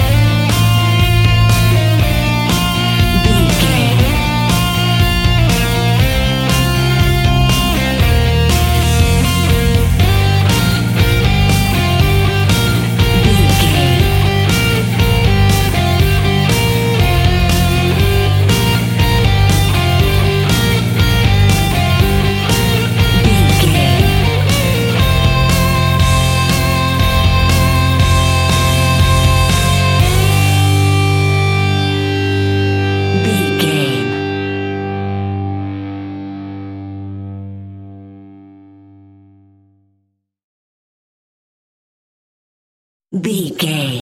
Epic / Action
Fast paced
Aeolian/Minor
hard rock
blues rock
distortion
rock instrumentals
rock guitars
Rock Bass
Rock Drums
heavy drums
distorted guitars
hammond organ